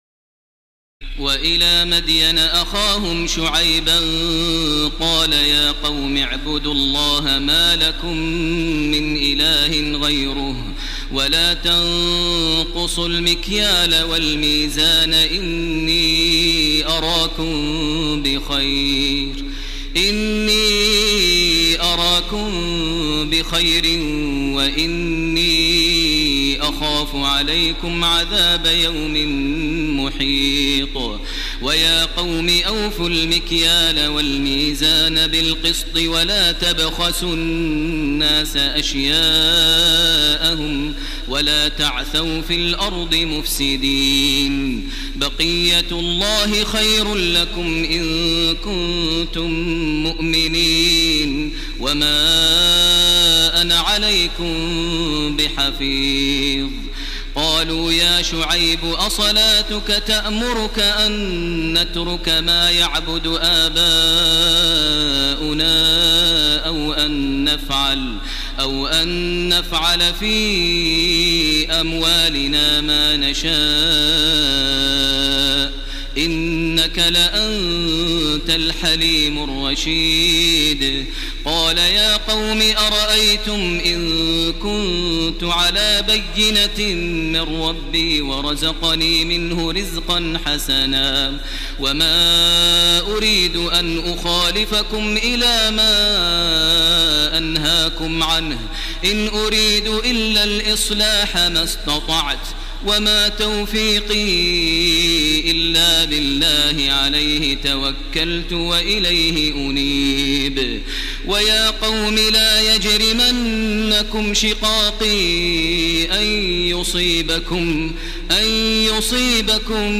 تراويح الليلة الثانية عشر رمضان 1430هـ من سورتي هود (84-123) و يوسف (1-53) Taraweeh 12 st night Ramadan 1430H from Surah Hud and Yusuf > تراويح الحرم المكي عام 1430 🕋 > التراويح - تلاوات الحرمين